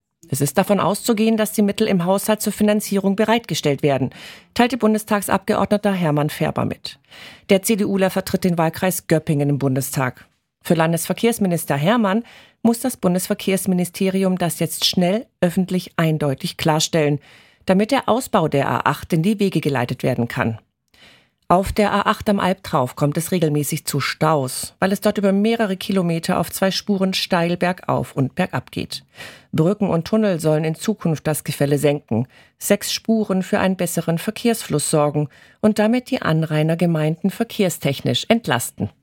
Hermann Färber, CDU-Bundestagsabgeordneter aus dem Wahlkreis Göppingen